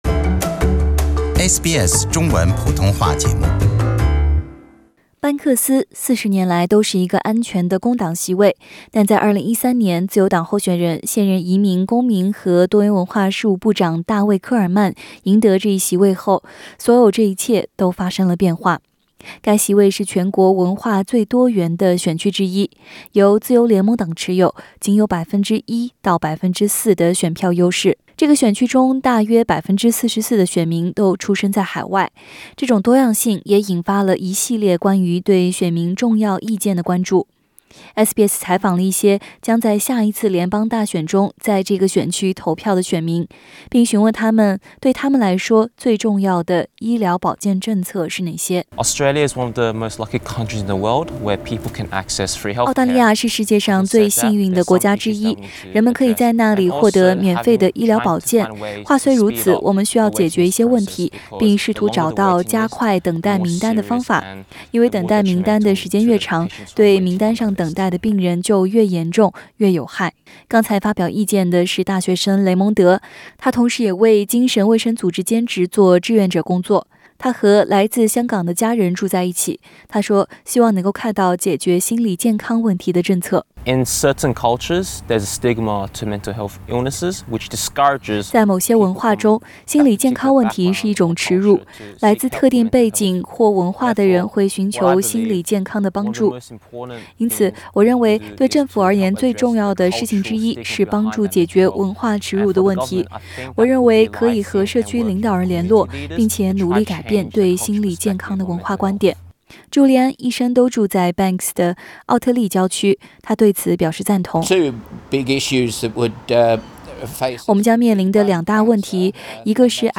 SBS的記者對邊緣選區之一Banks選區的選民進行了寀訪， 希望能了解他們關注澳大利亞未來政府的重點政策有哪些。